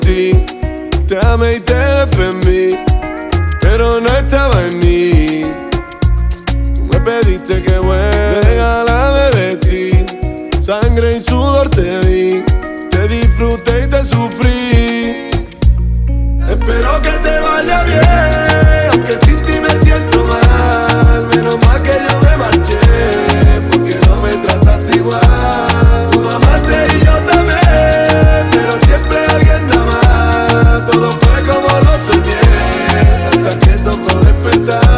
Жанр: Латино